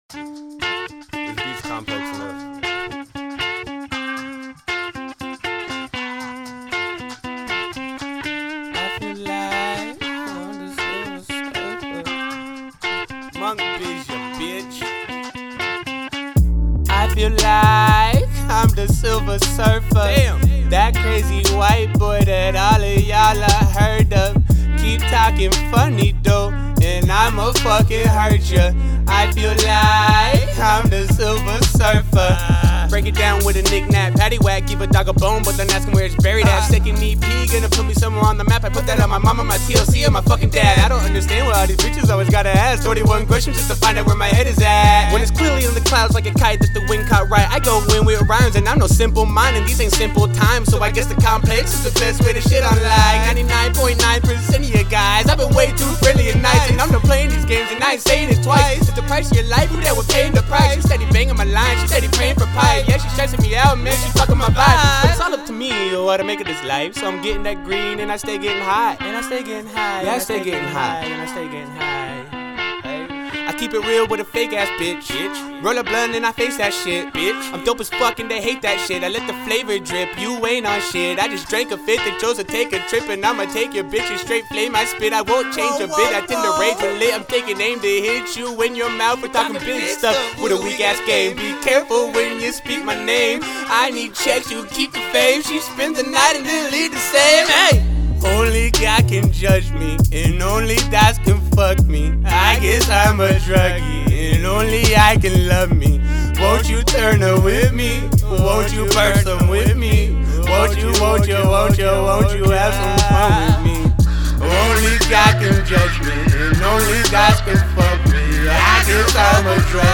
Seattle Hip Hop.